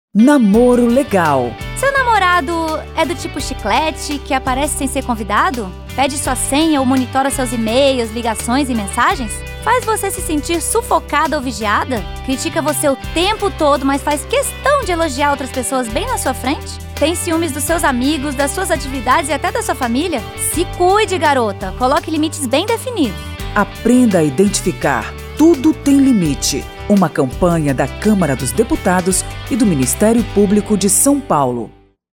São dezoito spots que falam sobre relacionamentos tóxicos.